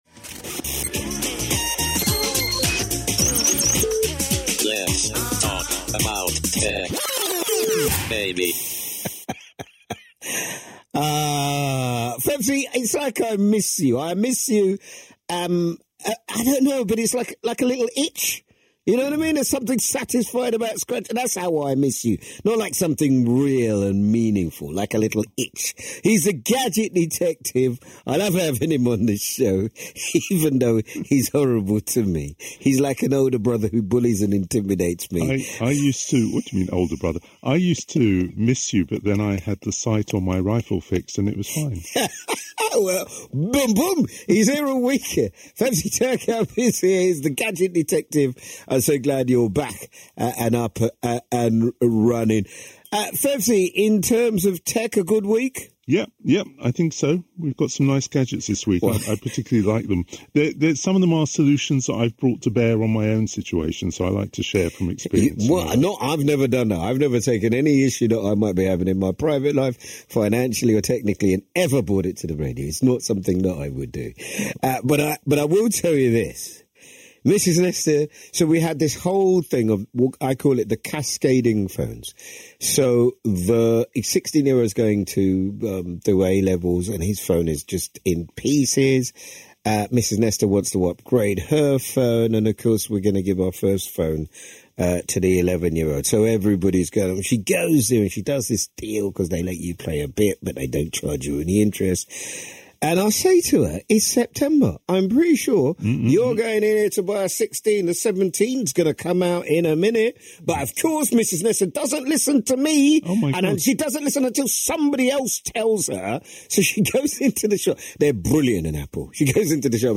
4th September 2025 - The Latest News & Reviews on BBC Radio London